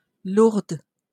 Lourdes (/lʊərd/,[3] also US: /lʊərdz/,[4][5] French: [luʁd]